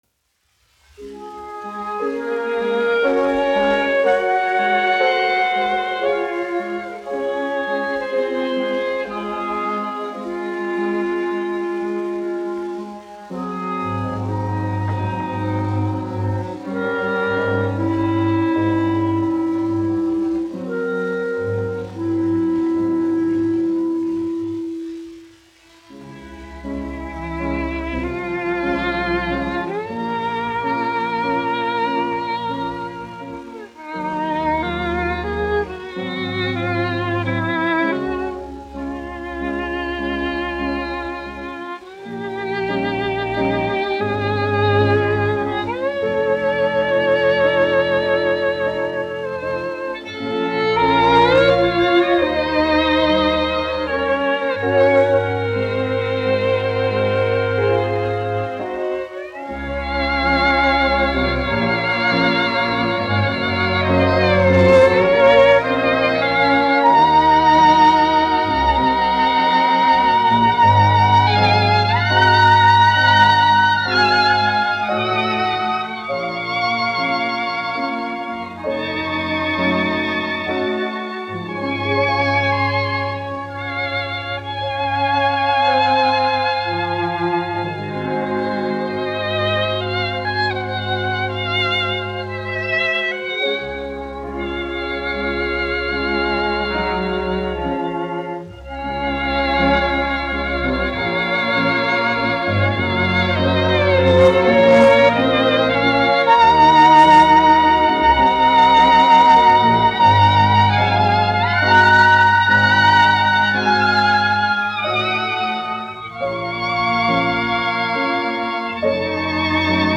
1 skpl. : analogs, 78 apgr/min, mono ; 25 cm
Orķestra mūzika, aranžējumi
Skaņuplate